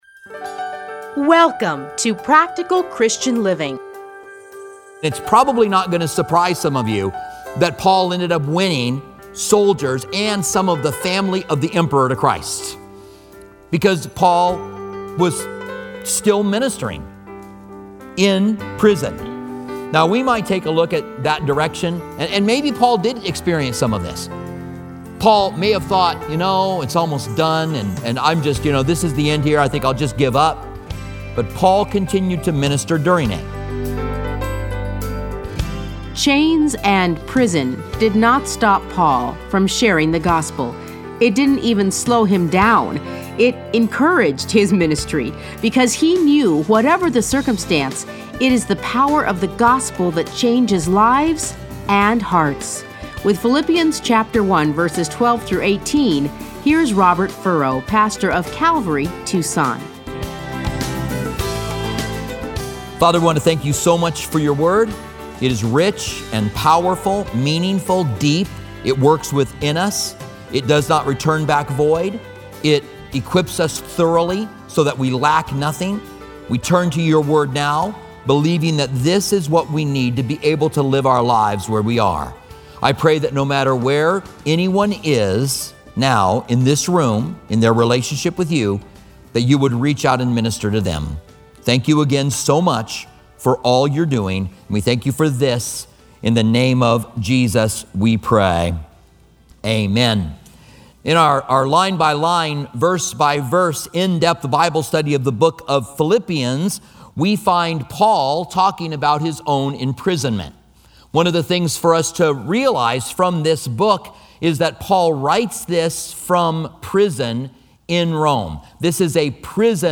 Listen to a teaching from Philippians 1:12-18.